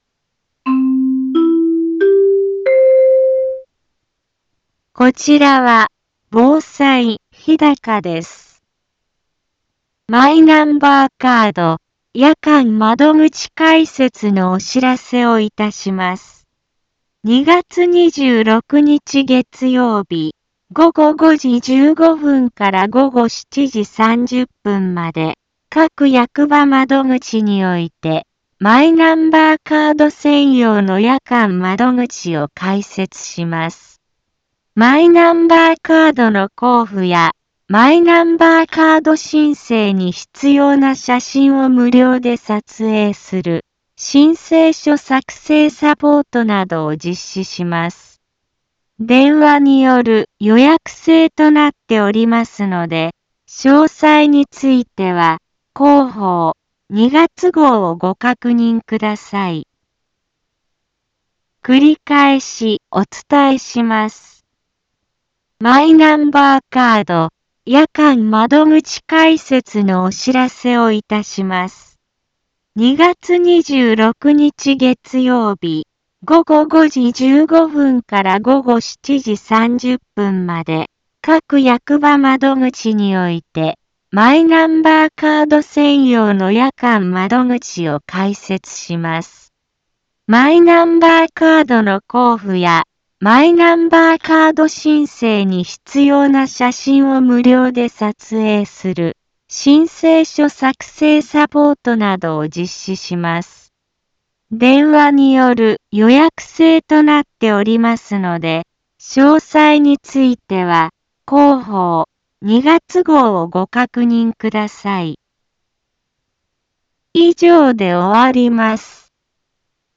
一般放送情報
Back Home 一般放送情報 音声放送 再生 一般放送情報 登録日時：2024-02-16 15:04:32 タイトル：マイナンバーカード夜間窓口開設のお知らせ インフォメーション： マイナンバーカード夜間窓口開設のお知らせをいたします。 2月26日月曜日、午後5時15分から午後7時30分まで、各役場窓口において、マイナンバーカード専用の夜間窓口を開設します。